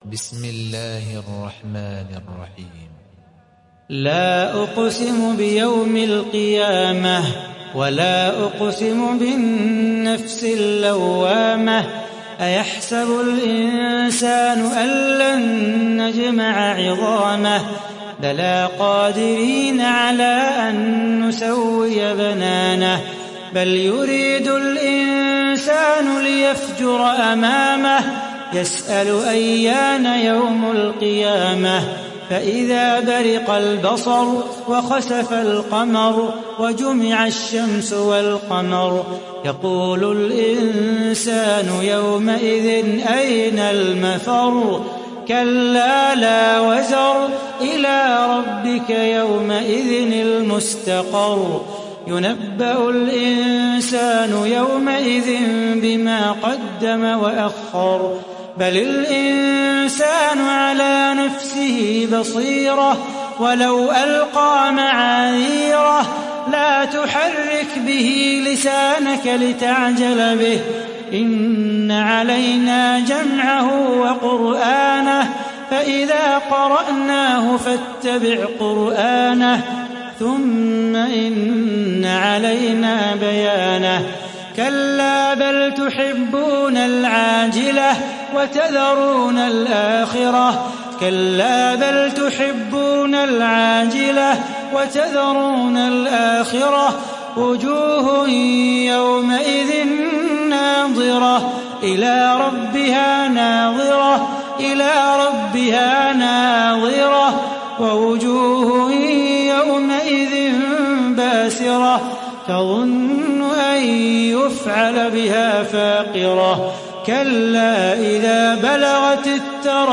Surah Al Qiyamah Download mp3 Salah Bukhatir Riwayat Hafs from Asim, Download Quran and listen mp3 full direct links